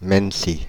[mɛnǝsi] je francouzská obec v departementu Essonne v regionu Île-de-France.
Fr-Paris--Mennecy.ogg